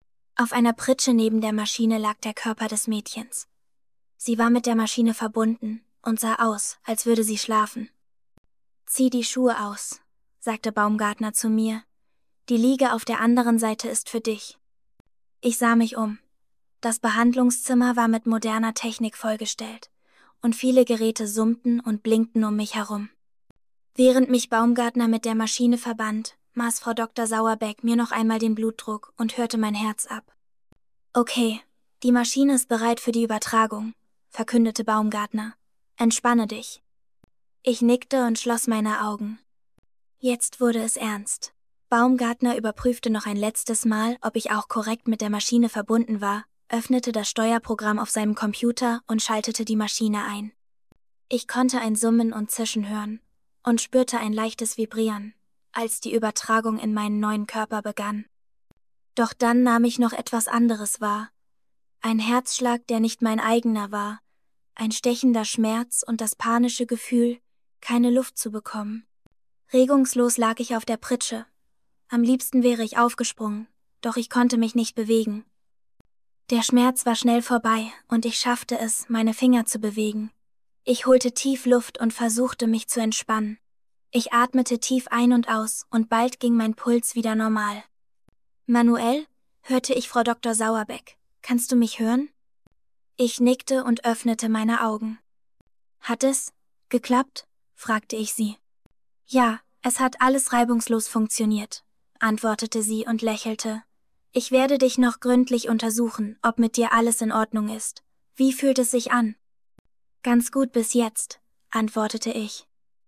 Text to Speech
Diesen Text habe ich nun von verschiedenen KI-Werkzeugen lesen lassen.
AIVocal bietet leider nur eine deutsche Stimme an: SweetLady.